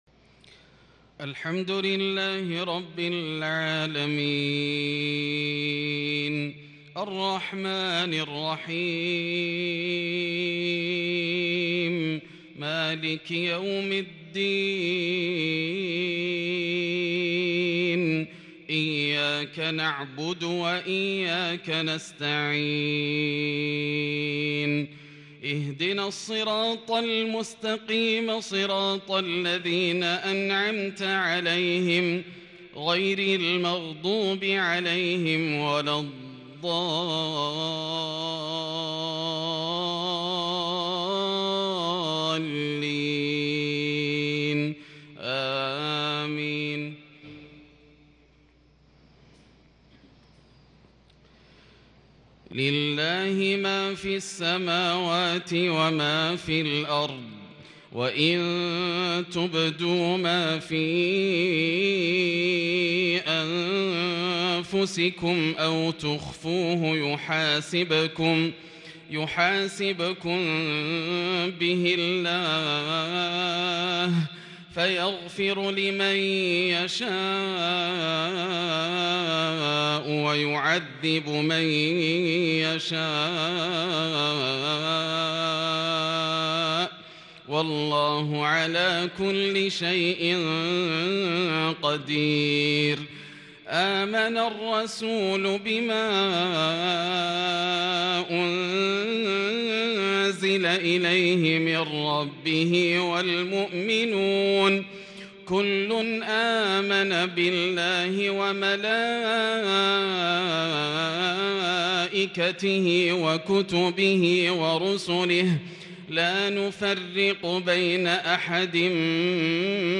صلاة المغرب للشيخ ياسر الدوسري 9 ذو الحجة 1442 هـ
تِلَاوَات الْحَرَمَيْن .